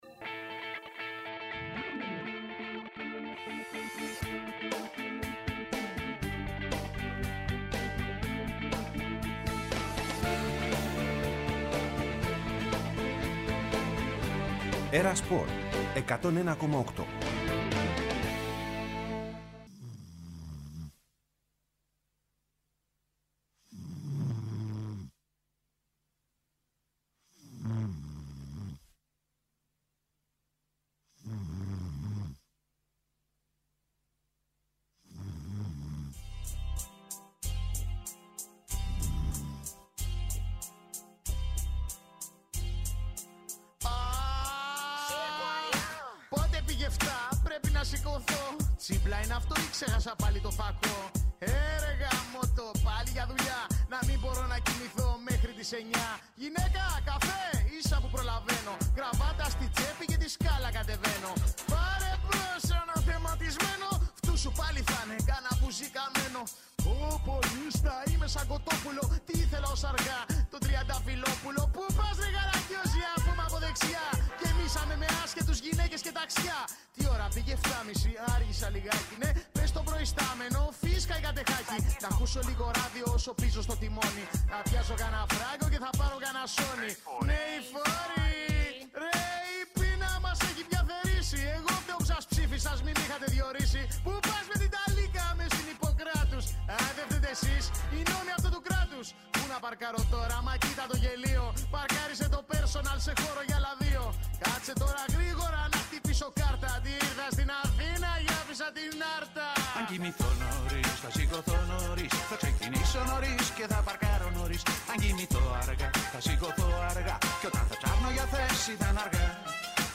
Συνεντεύξεις και ρεπορτάζ για όσα συμβαίνουν εντός και εκτός γηπέδων.